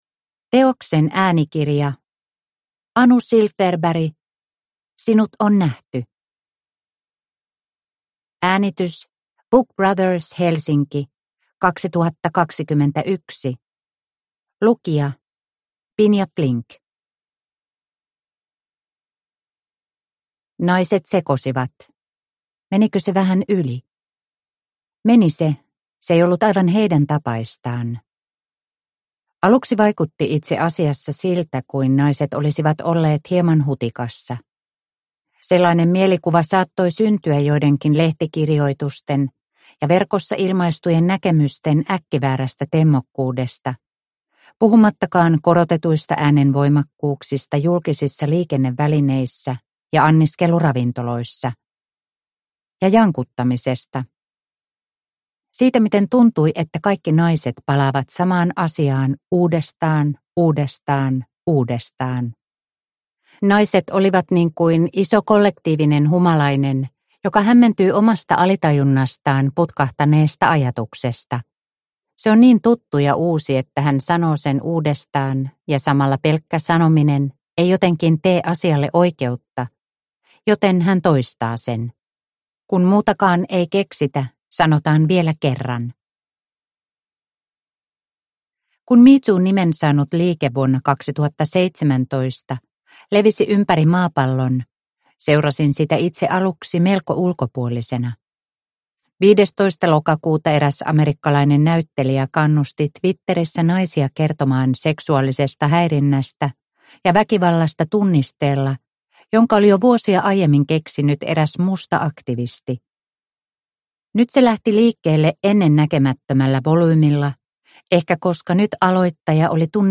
Sinut on nähty – Ljudbok – Laddas ner